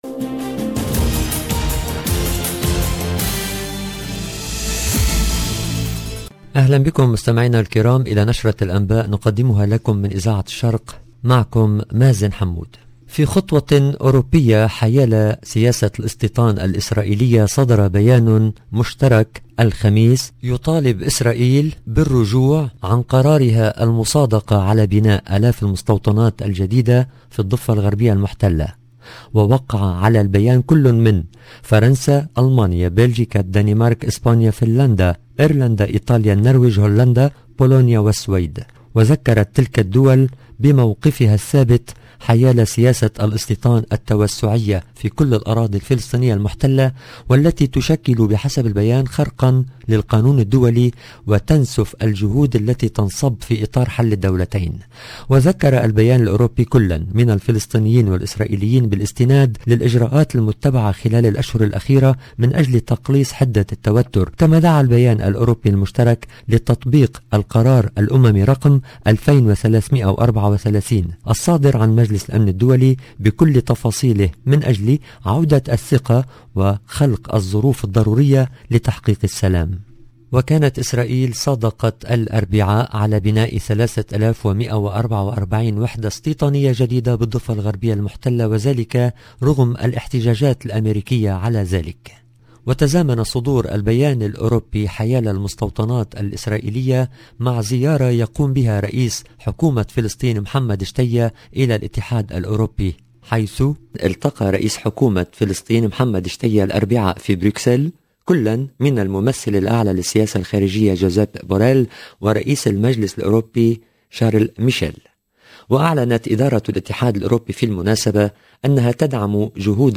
LE JOURNAL DU SOIR EN LANGUE ARABE DU 28/10/21